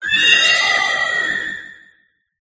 hatterene_ambient.ogg